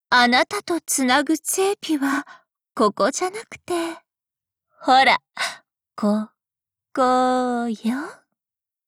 贡献 ） 协议：Copyright，人物： 碧蓝航线:波尔塔瓦语音 您不可以覆盖此文件。